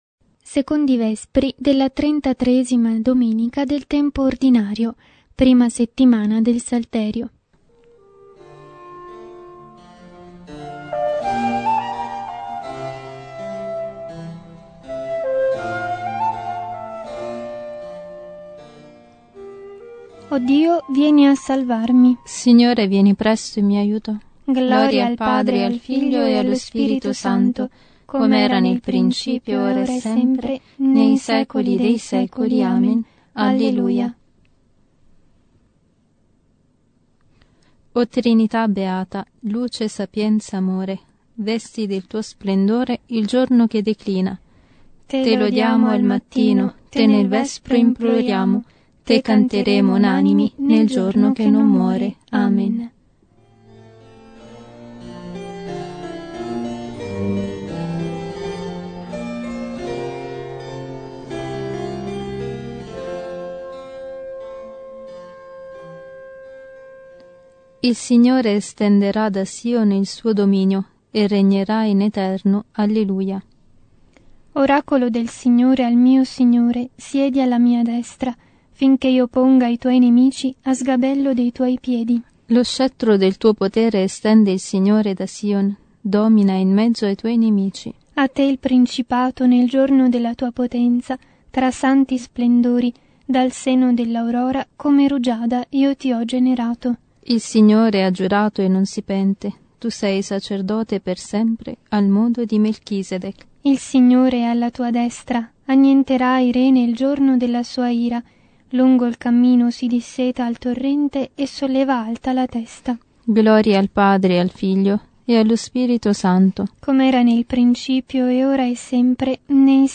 Vespri 33°D.T.O.-B (2°)